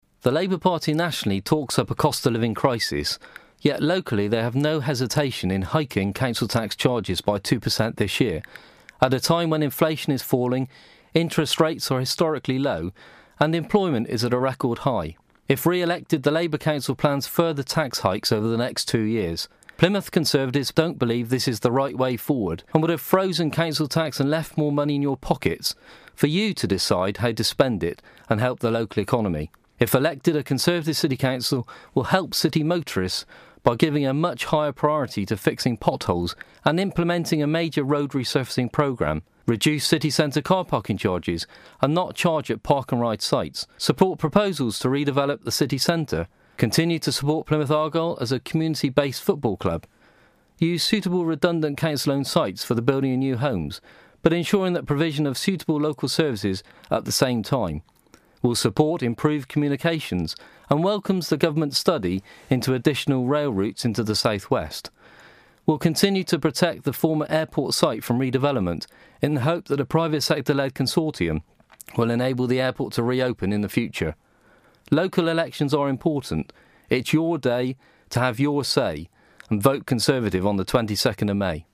LOCAL ELECTIONS 2014: Conservative Party Political Broadcast, Patrick Nicholson